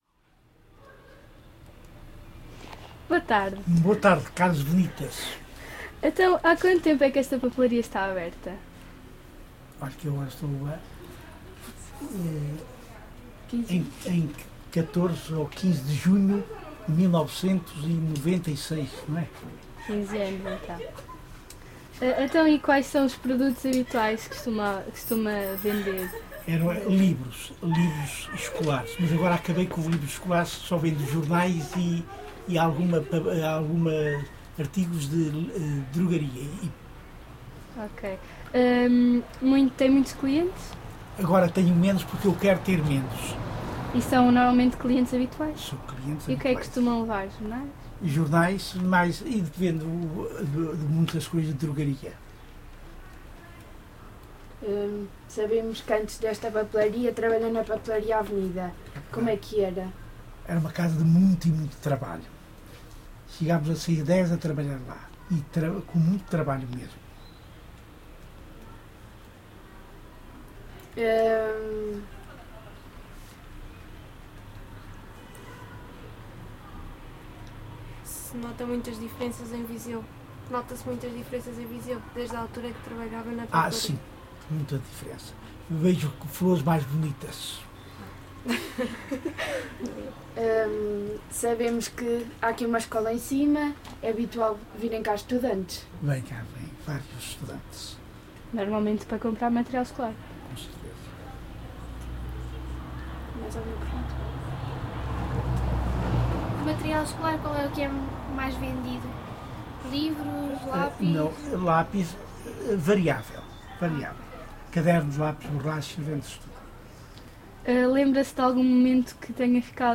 Gravado com Edirol R44 e um par de microfones de lapela Audio-Technica AT899.
Tipo de Prática: Inquérito Etnográfico, Inquérito Oral